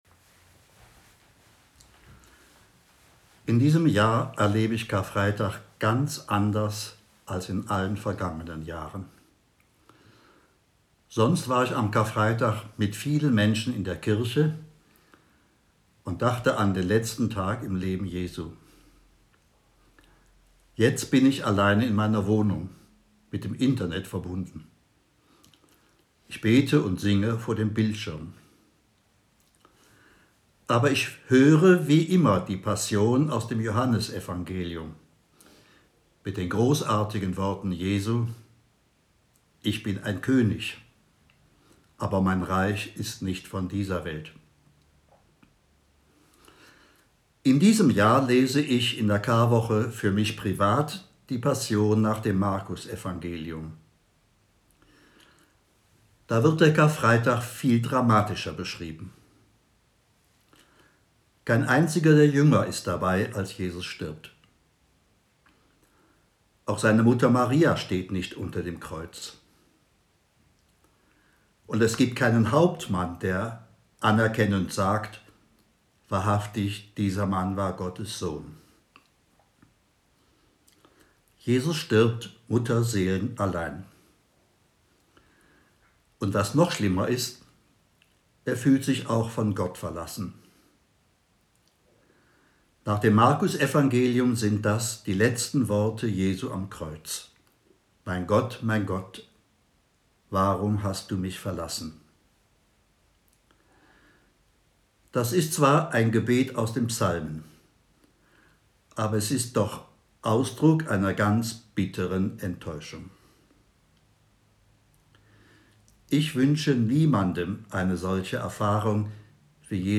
Ansprache